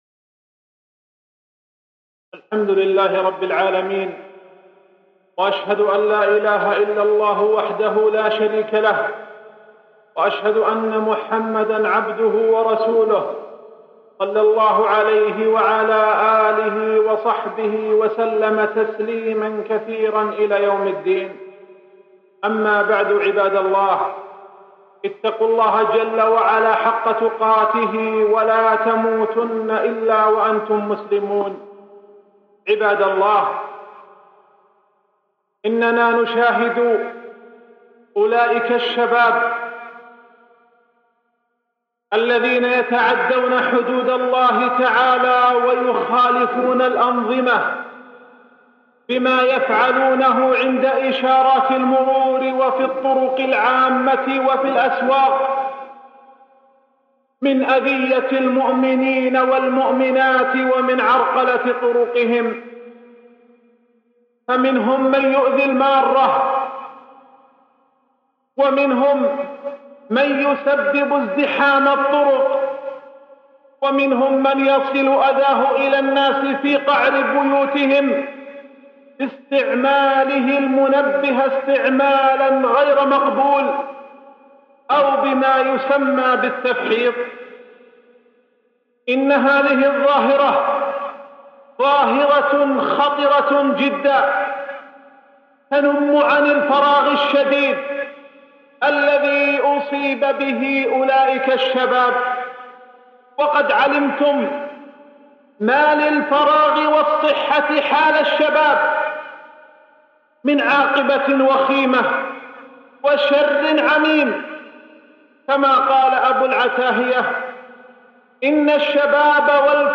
خطبة ظاهرة التفحيط الشيخ عبد السلام بن برجس آل عبد الكريم